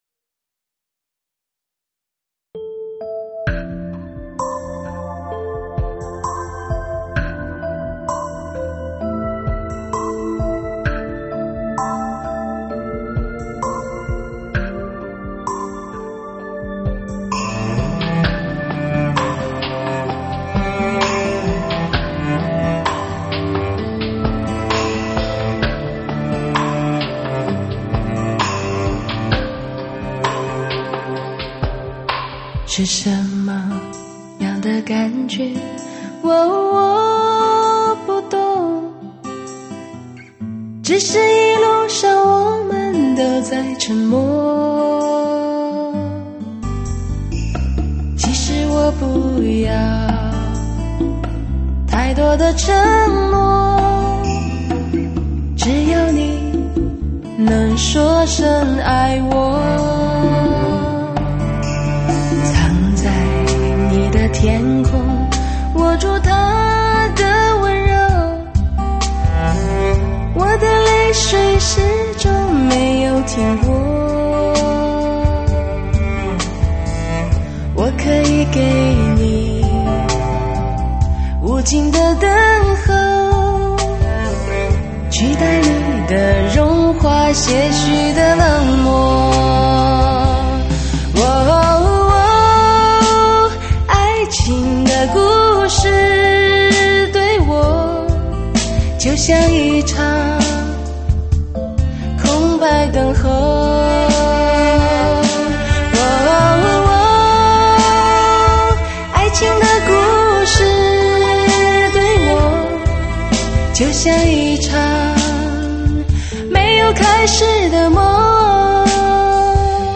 这声音仿佛是在轮回的时空发出来的，空旷而遥远，在